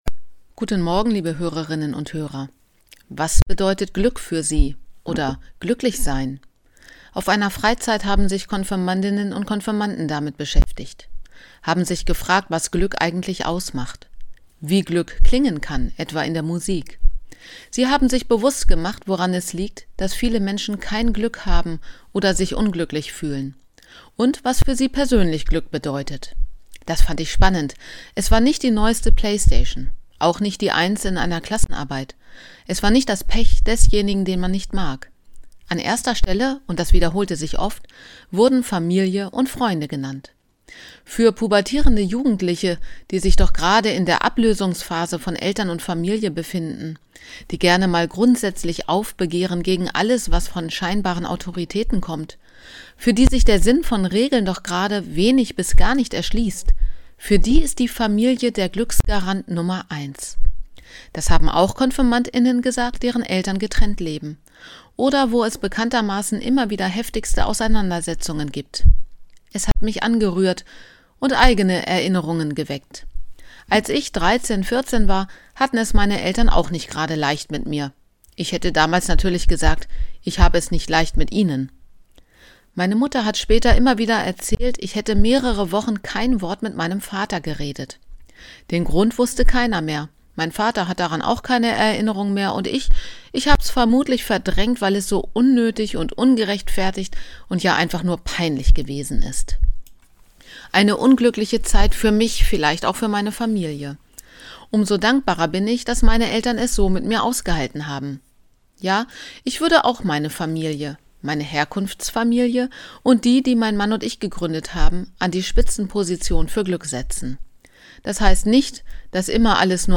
Radioandacht vom 31. März